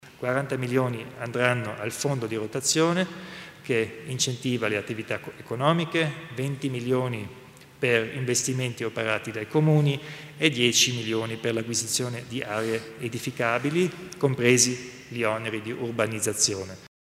Il Presidente Kompatscher illustra i dettagli dell'utilizzo dei fondi regionali